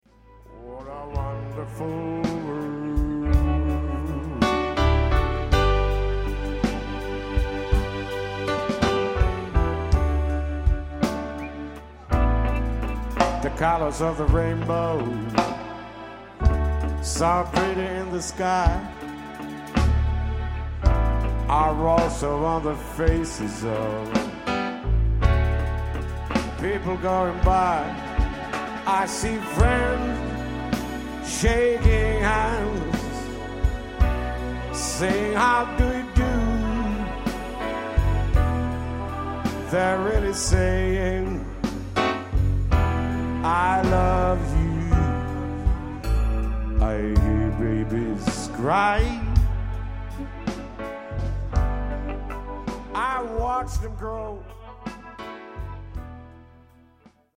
kosketinsoitin & laulu
kitara tai basso & laulu
rummut